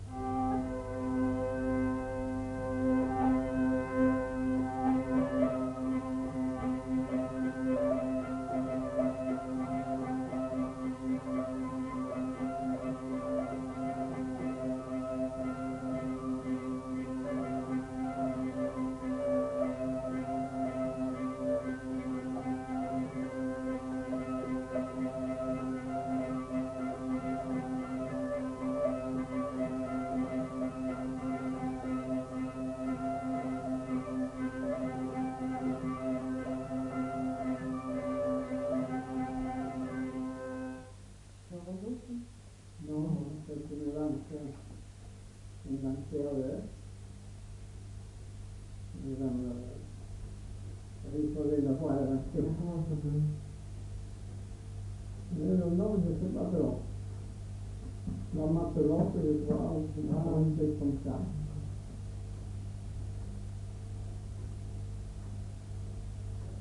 Aire culturelle : Petites-Landes
Lieu : Lencouacq
Genre : morceau instrumental
Instrument de musique : vielle à roue
Danse : pas de quatre